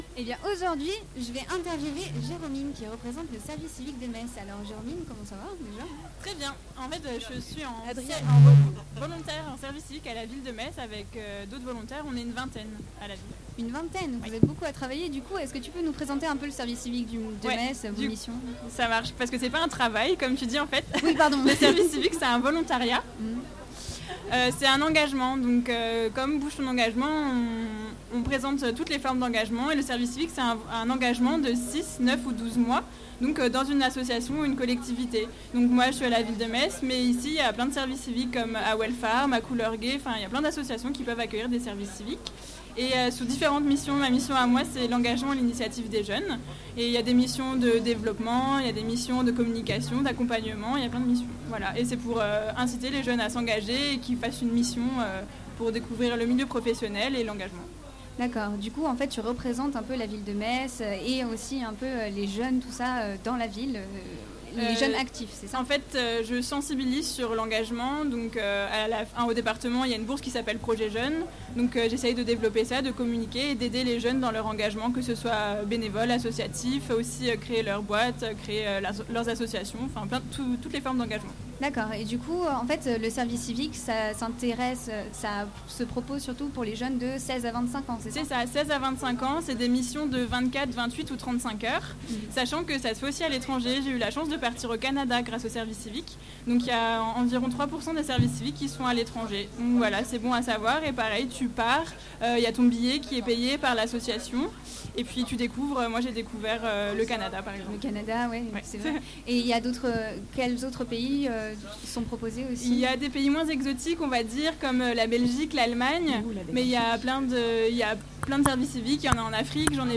Le dernier week-end de septembre a eu lieu la journée de clôture d’Etudiant dans ma ville à Metz, et l’équipe de Radio Campus Lorraine a bravé la pluie Place de la République pour vous faire découvrir les différentes associations qui animaient cet événement.
interview-service-civique.mp3